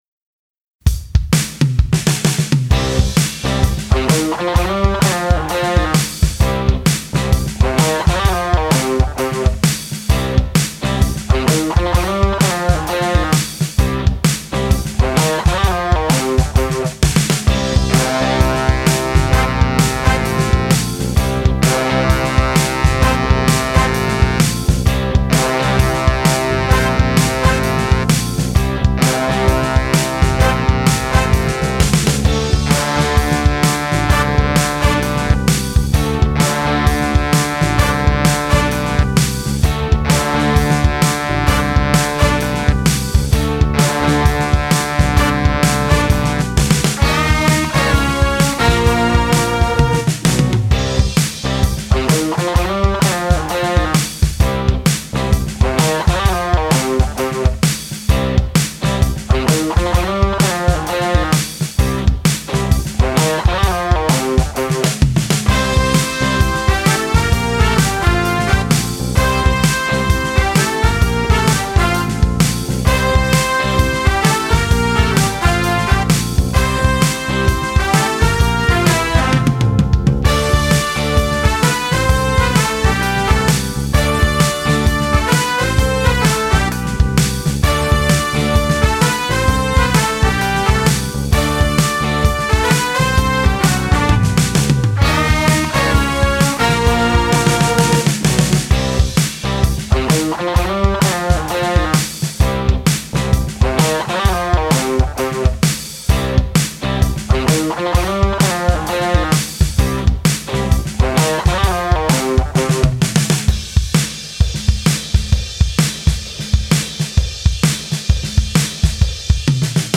bass track